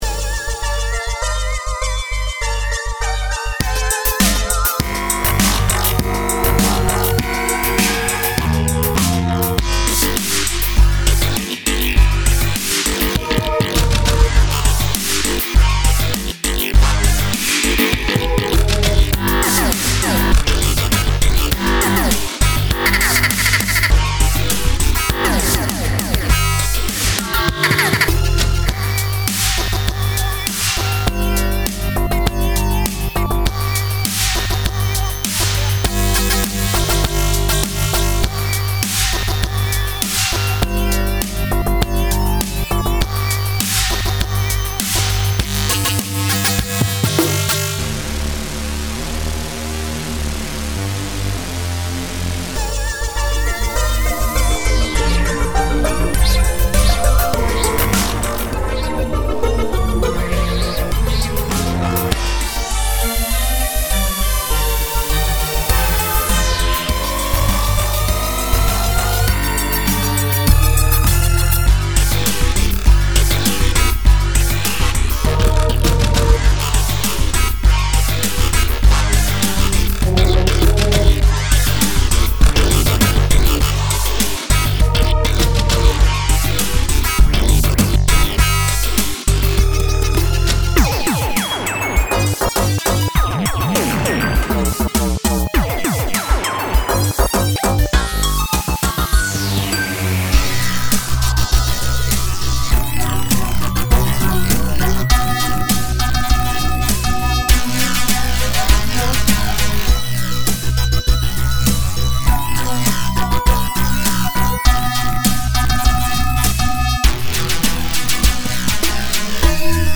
A dubstep inspired dungeon tune imagined for a boss called The Jackolantern.2023 update: increased the tempo for a more battle-appropriate version.